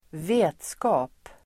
Ladda ner uttalet
vetskap substantiv, knowledge Uttal: [²v'e:tska:p] Böjningar: vetskapen Synonymer: kunnande, kunskap, kännedom Definition: kännedom Exempel: få vetskap om regeringens planer (learn about the government's plans)